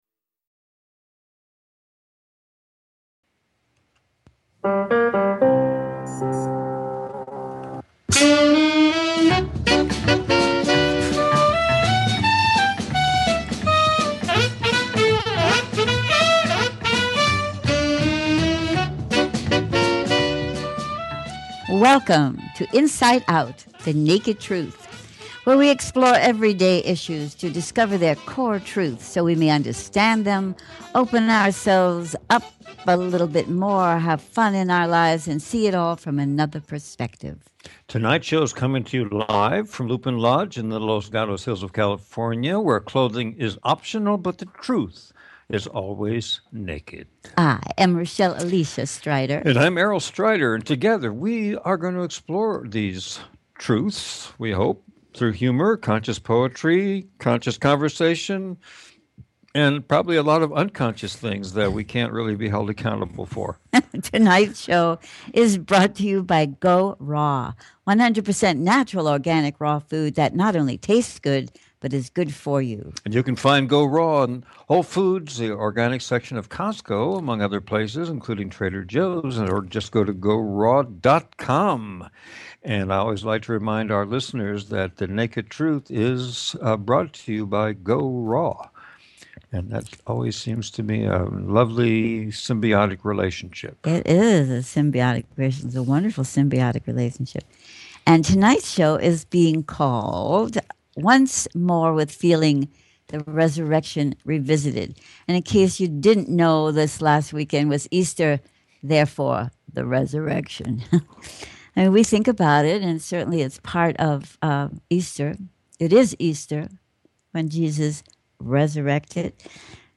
Talk Show Episode
A conversation about the Human Condition and what to make of it